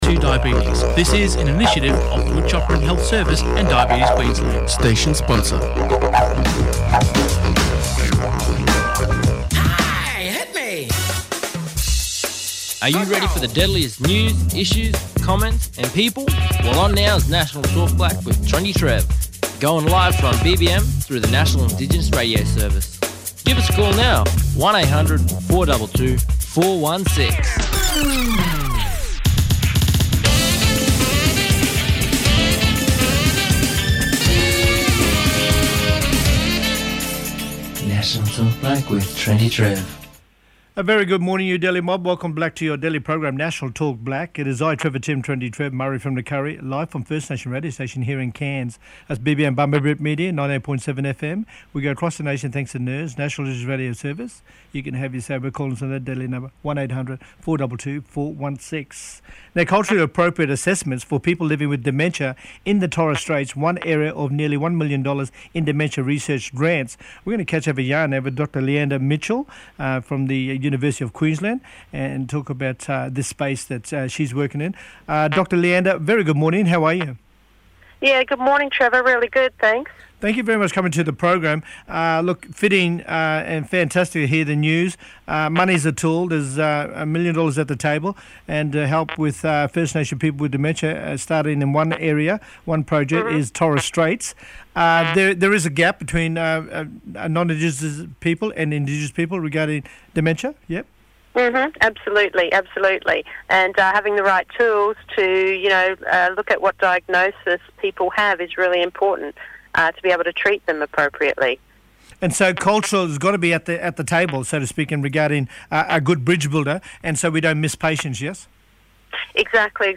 We also have our regular callers having their say on recent events on the program adding to the conversation.